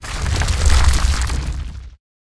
Index of /mclient/resources/newsound/mob/stonegolem/
boer_attack.ogg